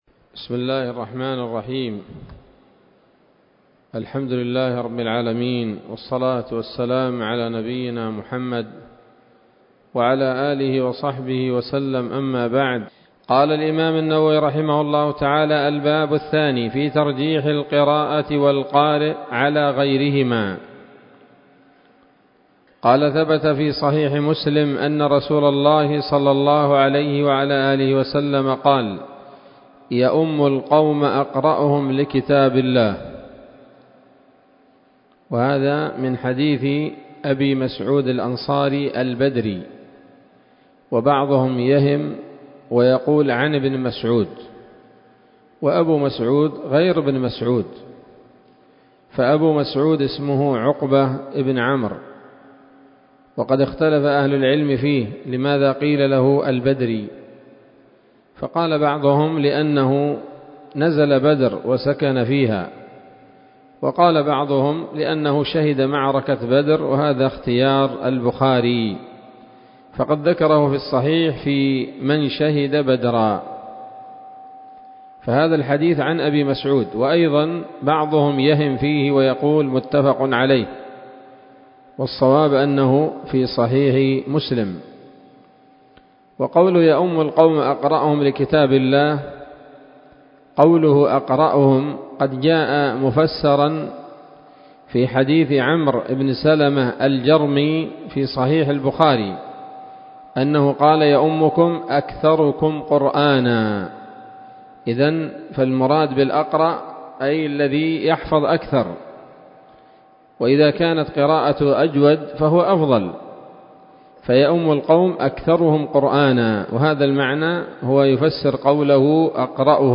الدرس الثالث من مختصر التبيان في آداب حملة القرآن للنووي